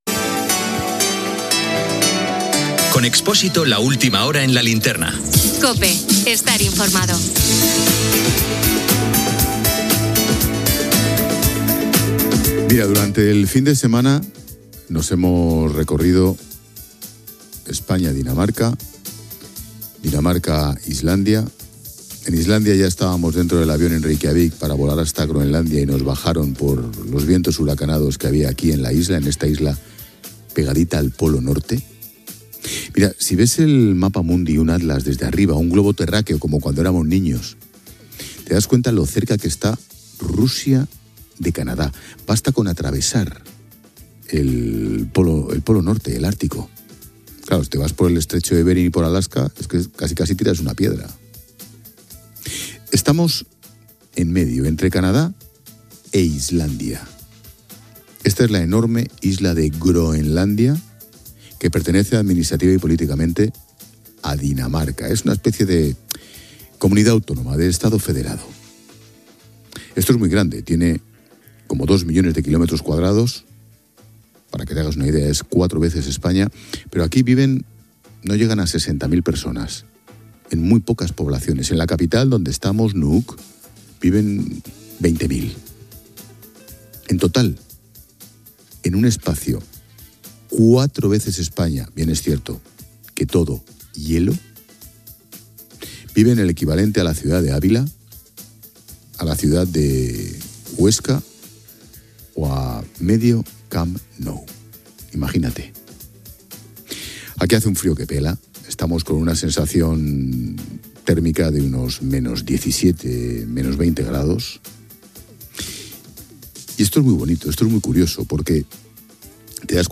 Careta, inici del programa, fet des de Groenlàndia.
Informatiu
FM